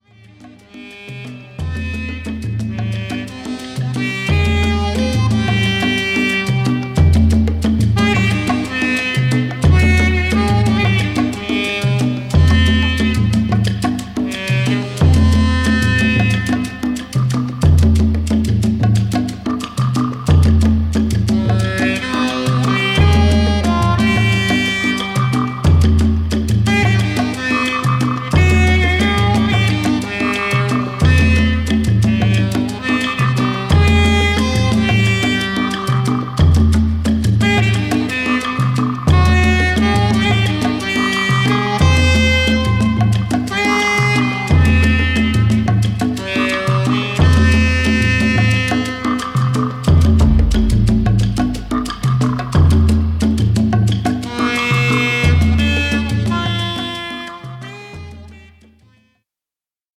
ホーム ｜ JAZZ / JAZZ FUNK / FUSION > JAZZ